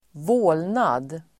Uttal: [²v'å:lnad]